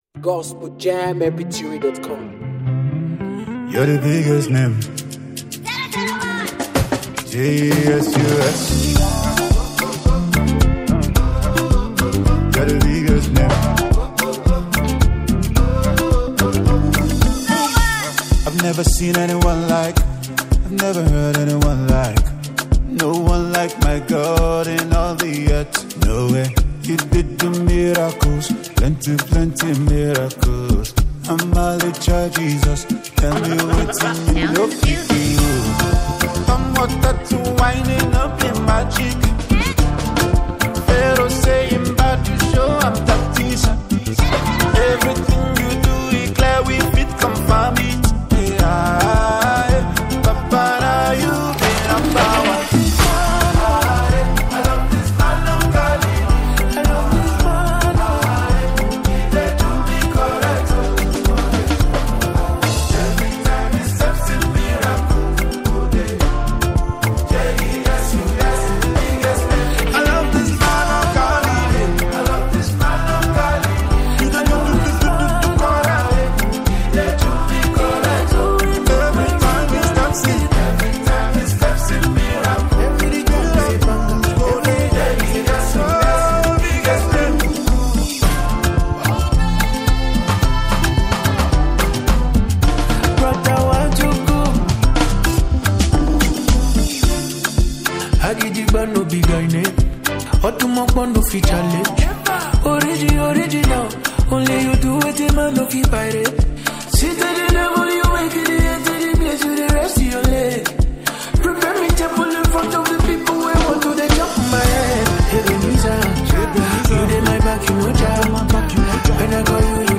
a powerful Afro-gospel praise anthem